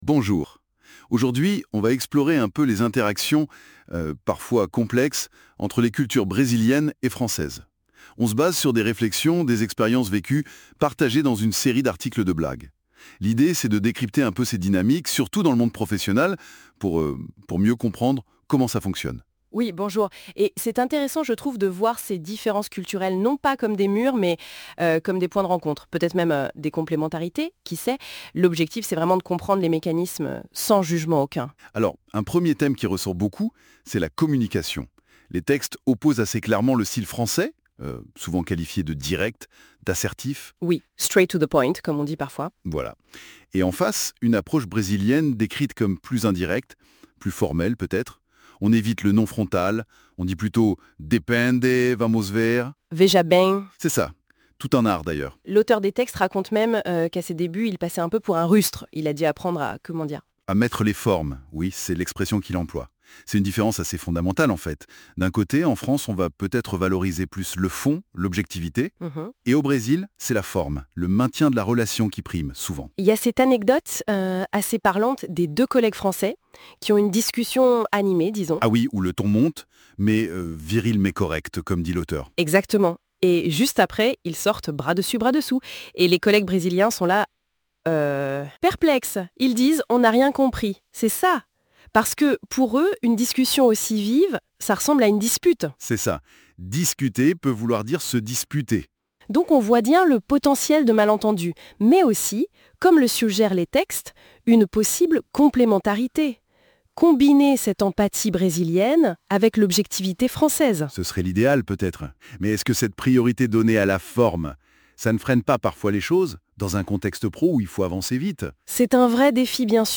Ecoutez une conversation basée sur les articles sociologie